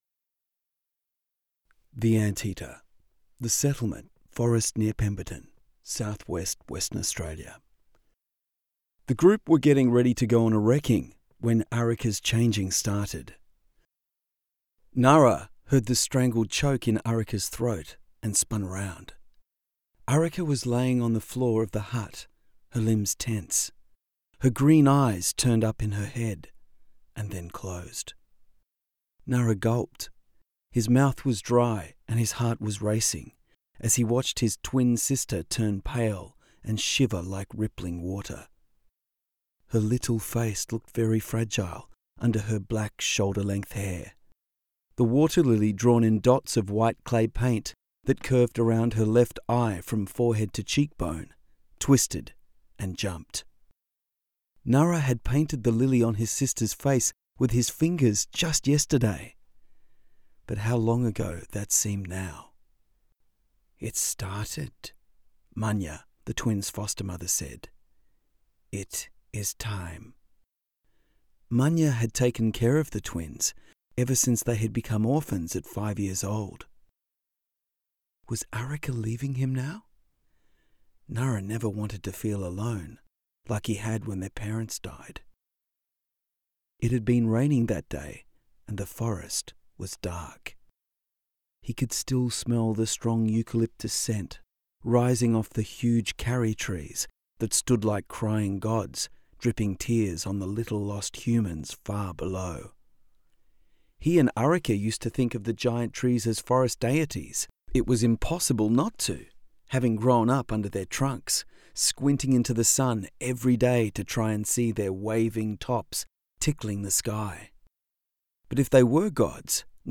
read by Australian professional voice artist